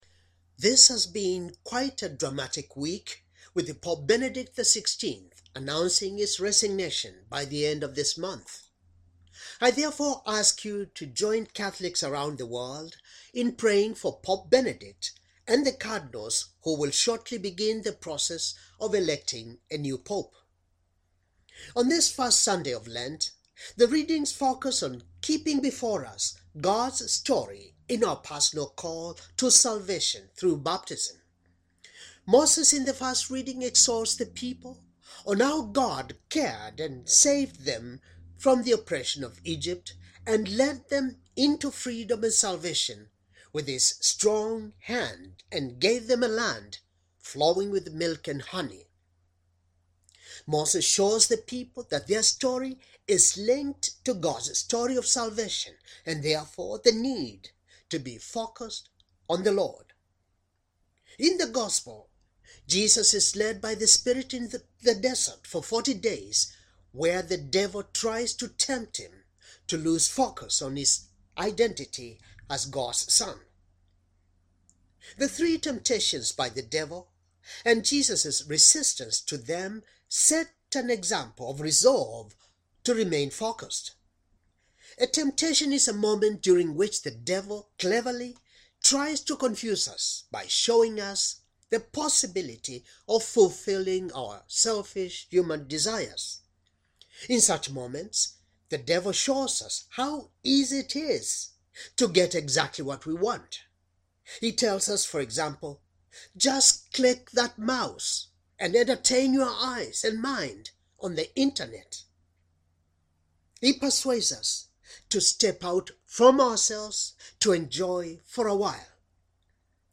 Homily for First Sunday of Lent, Year C